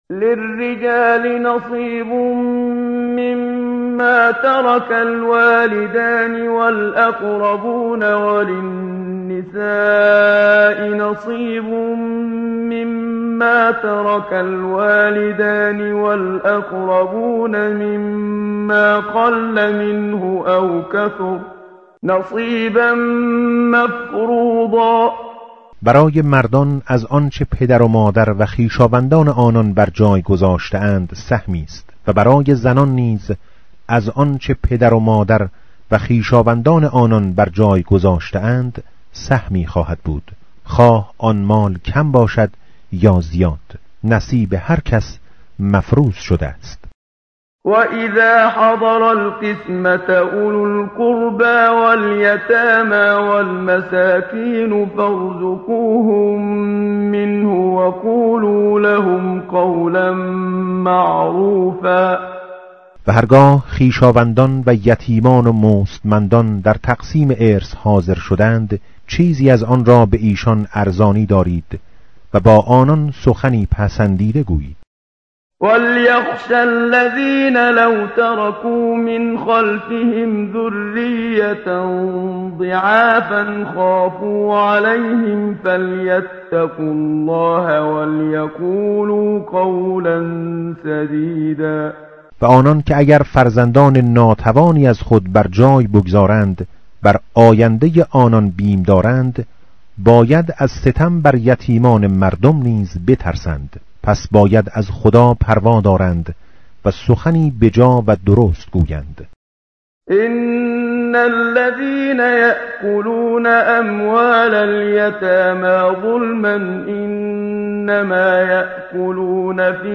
متن قرآن همراه باتلاوت قرآن و ترجمه
tartil_menshavi va tarjome_Page_078.mp3